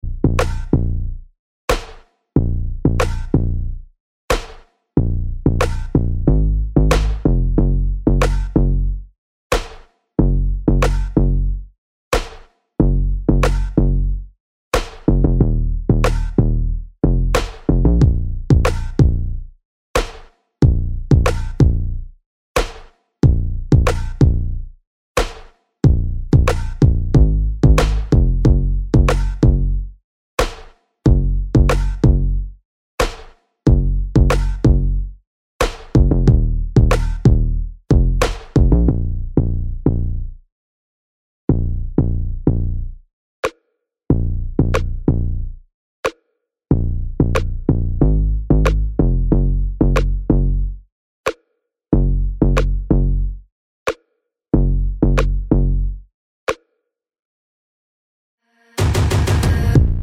no Backing Vocals Pop (2010s) 2:45 Buy £1.50